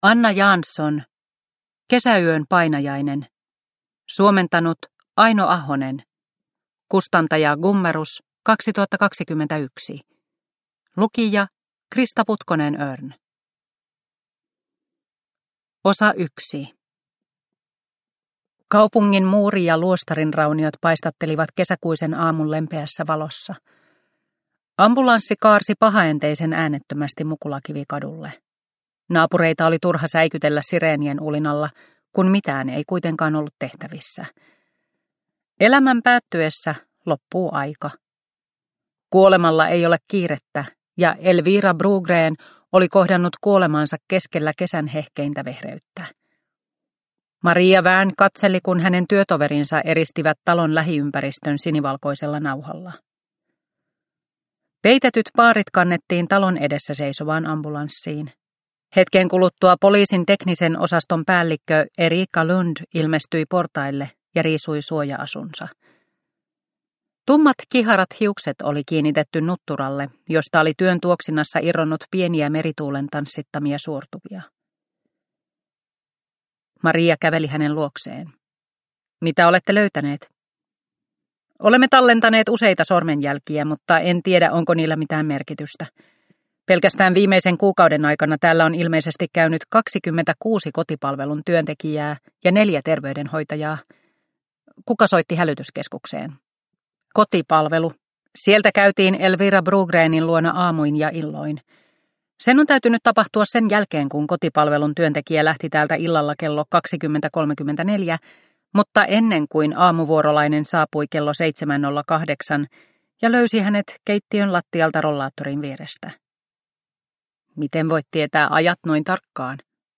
Kesäyön painajainen – Ljudbok – Laddas ner